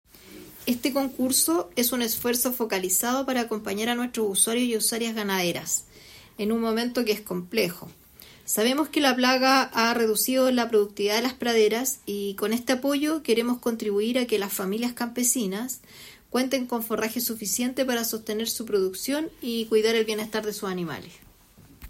Directora regional de INDAP